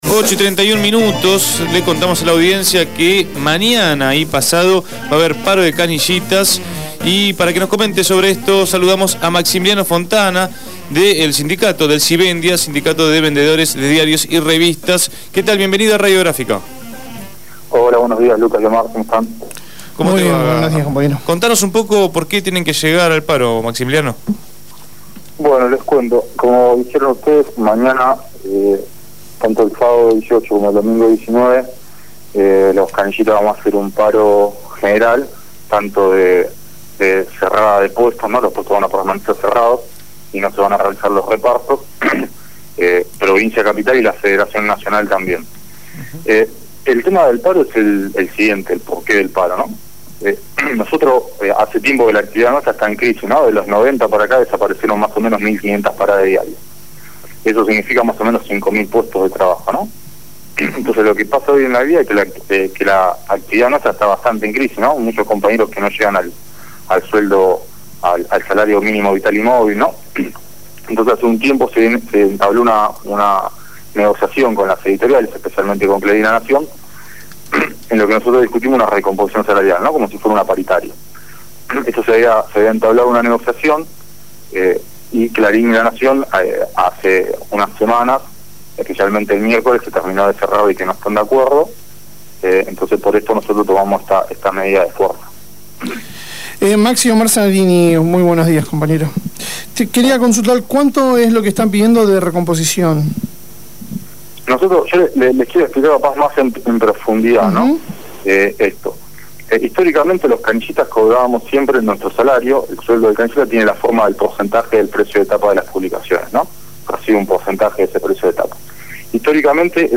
habló en Punto de Partida.